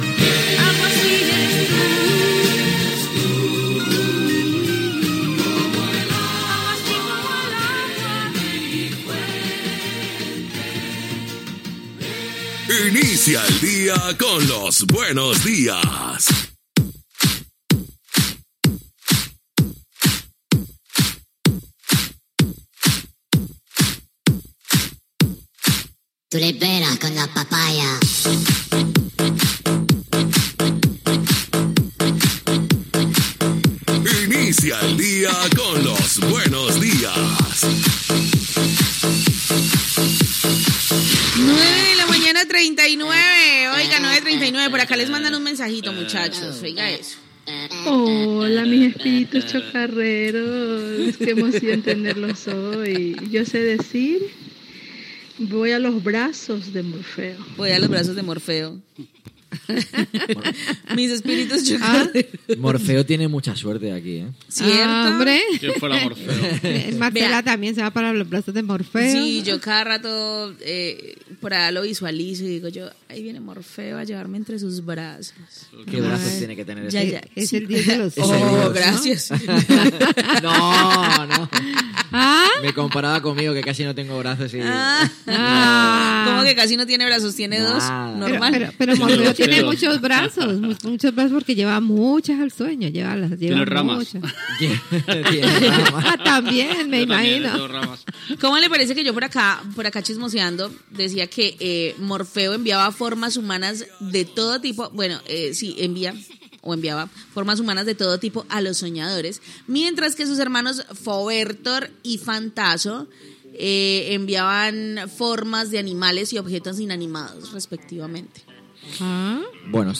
Idicatiu del programa, comentari dels presentadors sobre Morfeo, missatges de l'audiència, comentari sobre els somnis, tema musical, publicitat, tema musical, indicatiu del programa, hora, missatges de l'audiència Gènere radiofònic Entreteniment